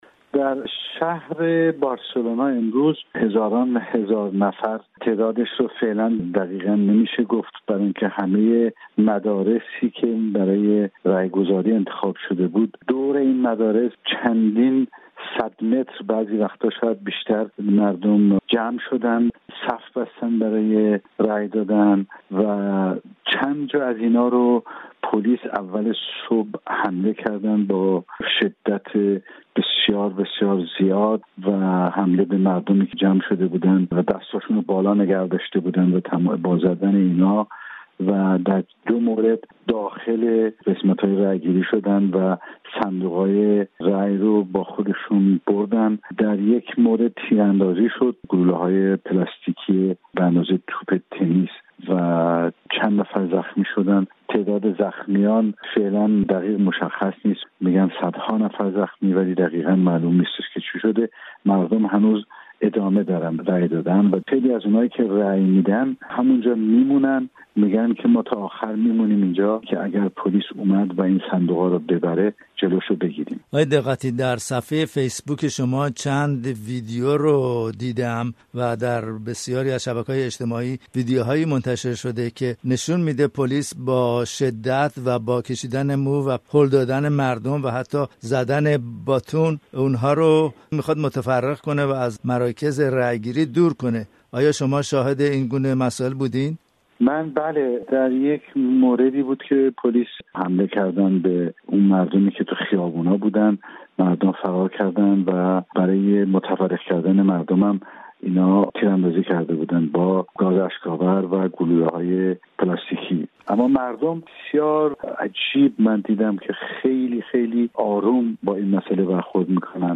همه‌پرسی کاتالونیا و حواشی آن؛ روایت رضا دقتی عکاس خبری سرشناس ایرانی